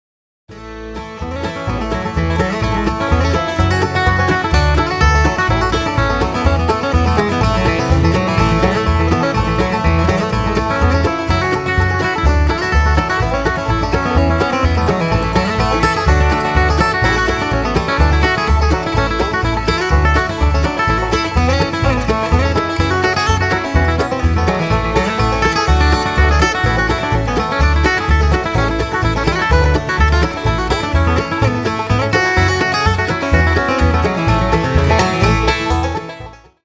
CHEROKEE SHUFFLE | GUITAR
Cherokee Shuffle Traditional; arr. Josh Williams (.pdf file)